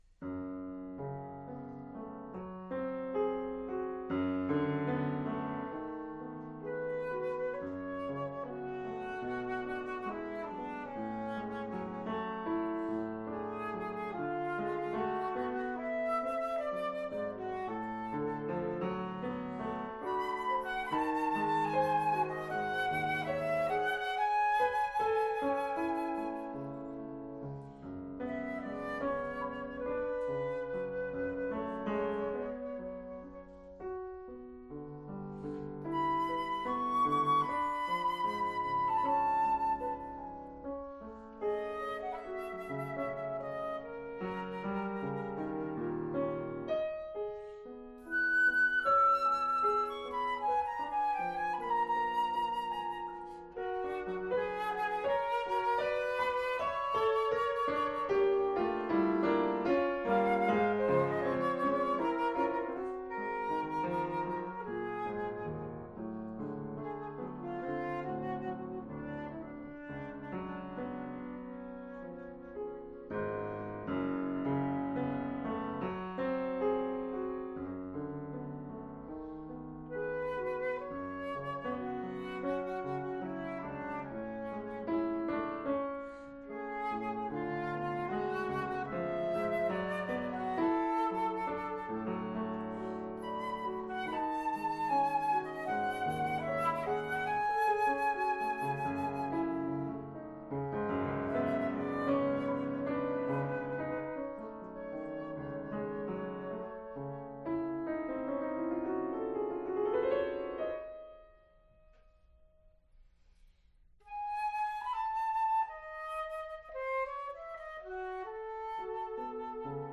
for piano LH and flute
from a random perf last year